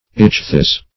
ichthys - definition of ichthys - synonyms, pronunciation, spelling from Free Dictionary Search Result for " ichthys" : The Collaborative International Dictionary of English v.0.48: Ichthys \Ich"thys\, n. [NL., fr. Gr.